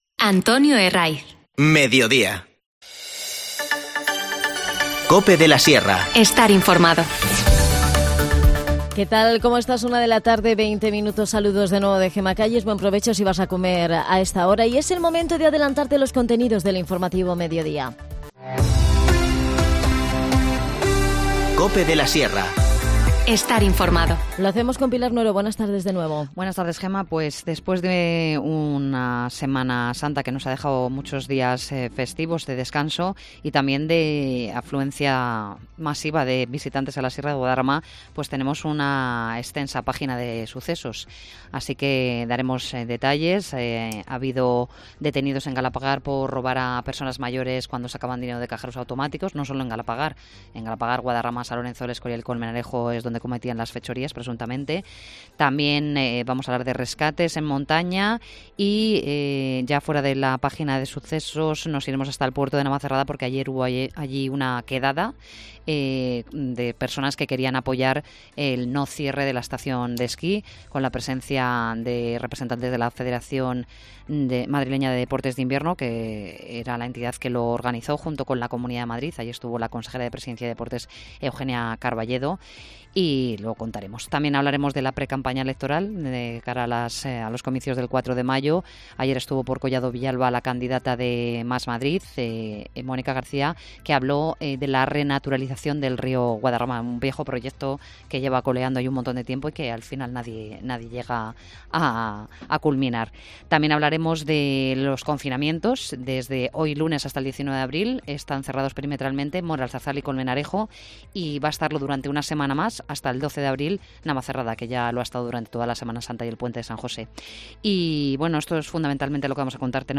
INFORMACIÓN LOCAL
Las desconexiones locales son espacios de 10 minutos de duración que se emiten en COPE, de lunes a viernes.